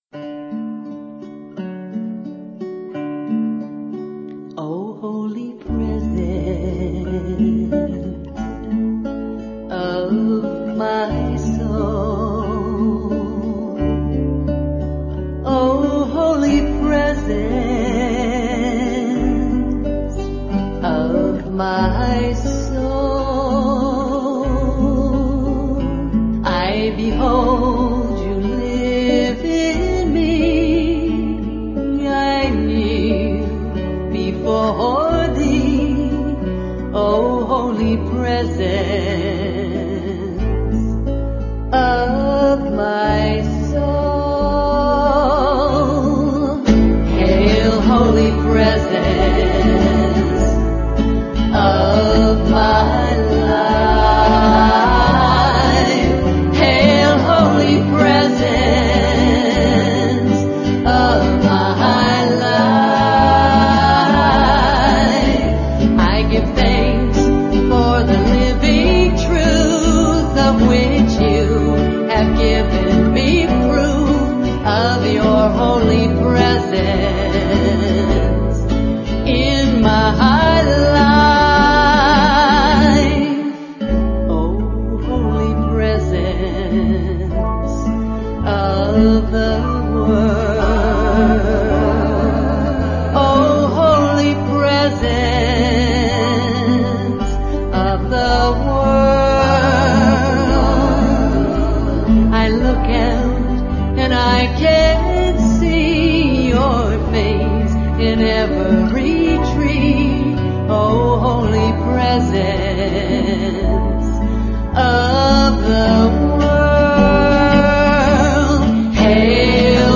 1. Devotional Songs
Major (Shankarabharanam / Bilawal) 8 Beat
8 Beat / Keherwa / Adi